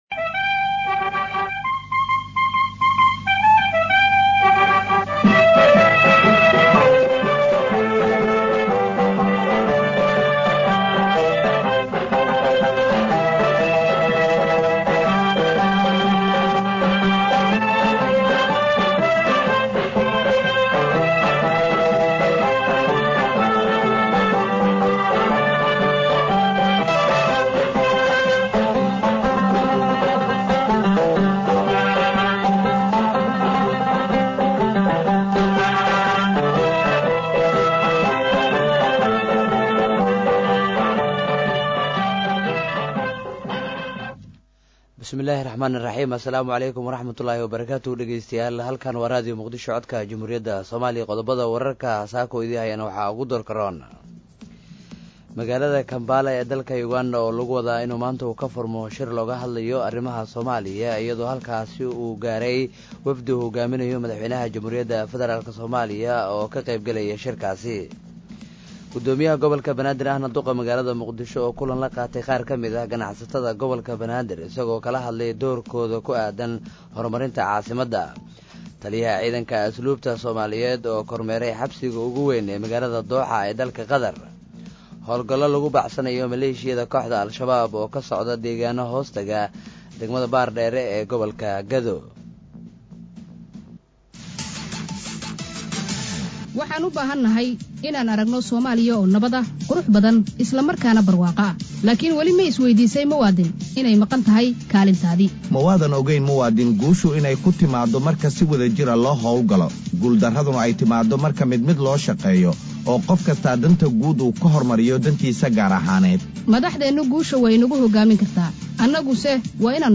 Dhageyso Warka Subax ee Raio Muqdisho